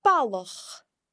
The broad L can be heard in the middle of a word in balach (a boy):